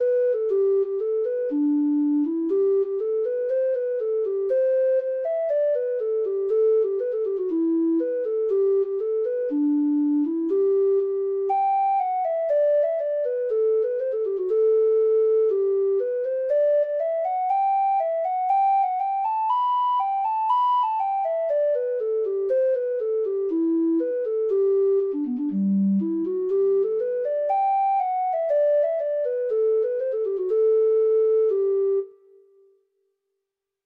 Traditional Music of unknown author.
Irish